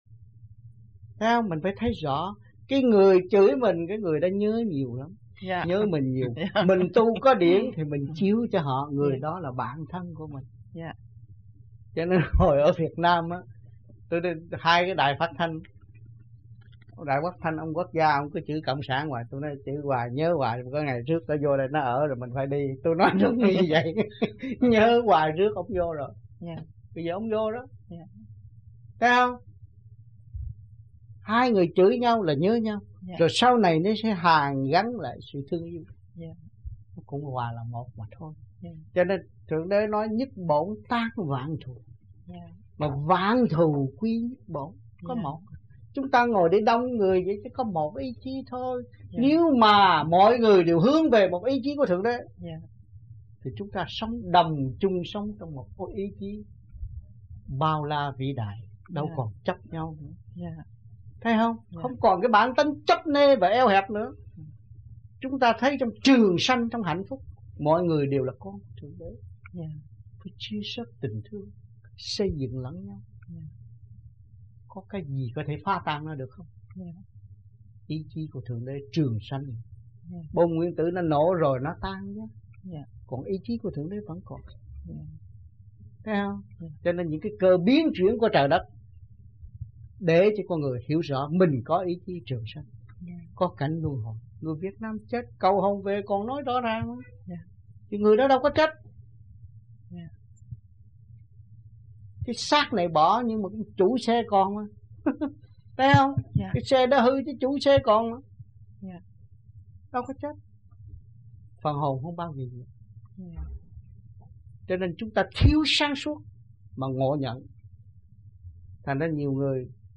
1980-11-11 - NANTERRE - THUYẾT PHÁP 2